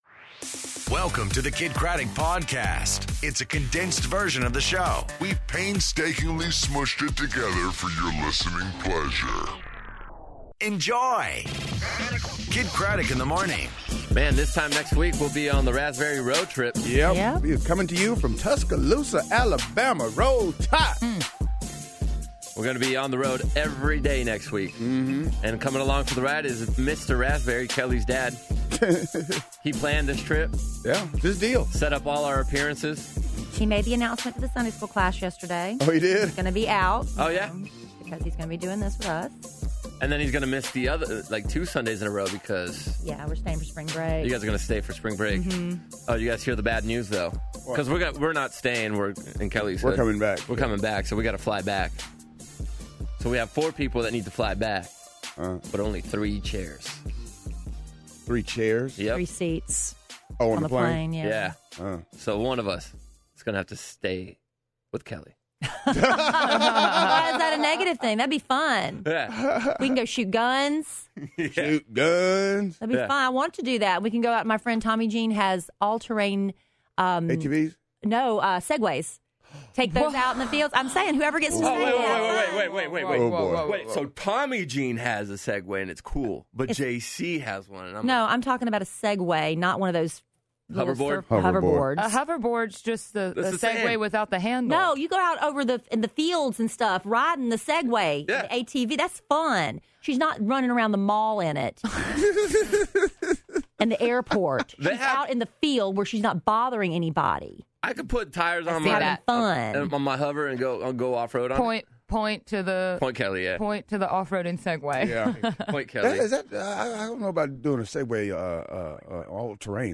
The Blue Man Group In Studio!!!!!